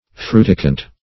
Meaning of fruticant. fruticant synonyms, pronunciation, spelling and more from Free Dictionary.
Search Result for " fruticant" : The Collaborative International Dictionary of English v.0.48: Fruticant \Fru"ti*cant\, a. [L. fruticans, p. pr. of fruticare, to become bushy, fr. frutex, fruticis, shrub.]